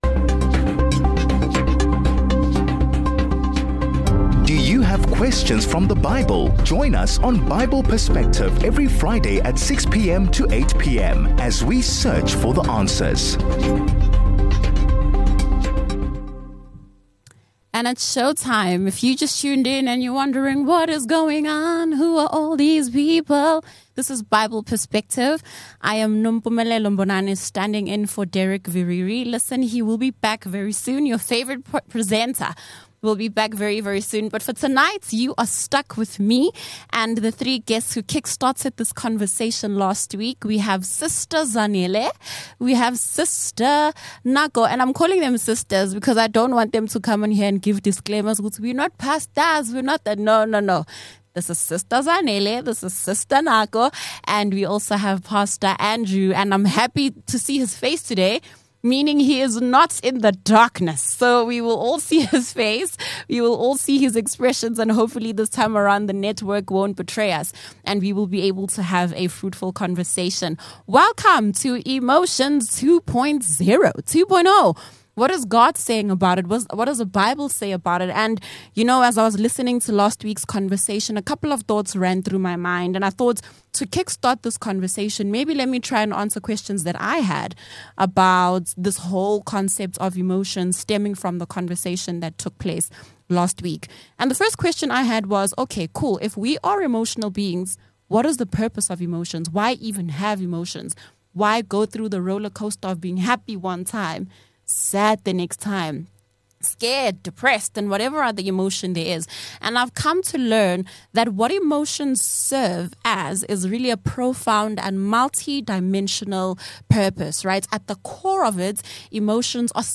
Beyond people's opinions, we seek for the Bible's Perspective about all this, as our panelists return to take us through God's word, making it Practical applicable